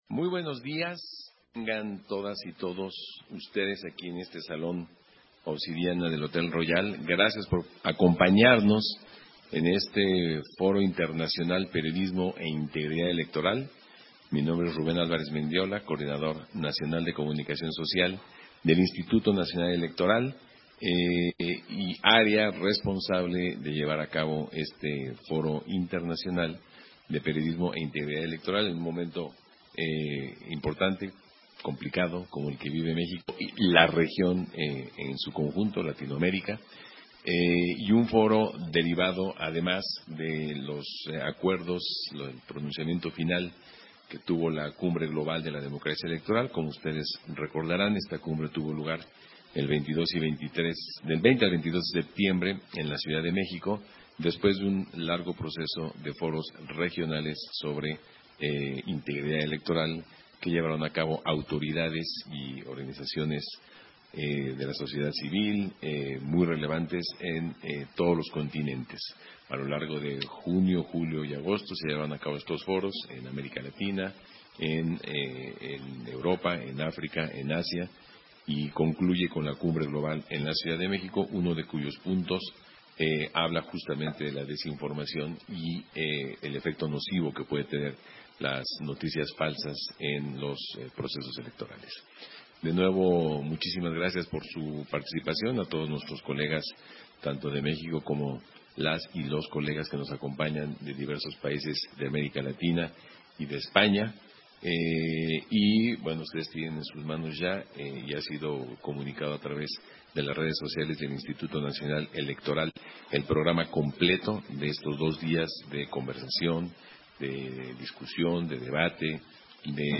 Versión estenográfica de la inauguración del Foro internacional: La importancia del periodismo en la integridad electoral, en el marco de la Cumbre Global de la Democracia Electoral